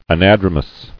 [a·nad·ro·mous]